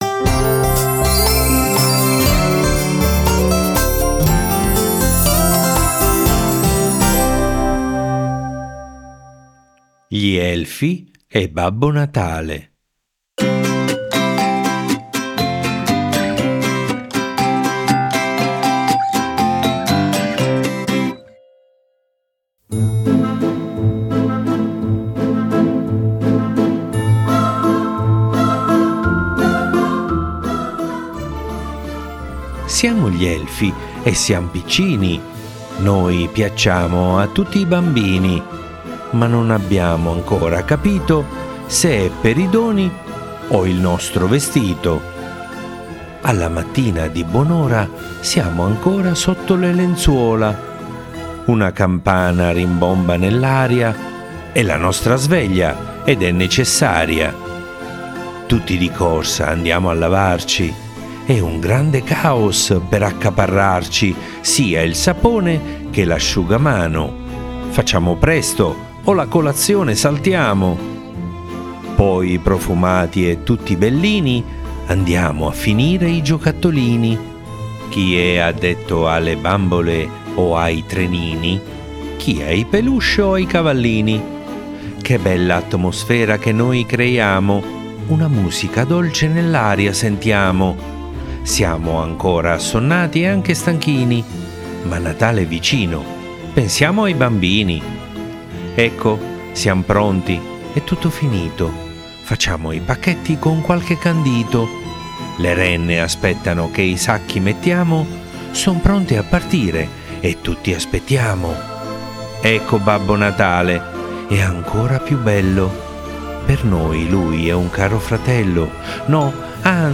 Questa sera vi leggo una bellissima filastrocca di Natale!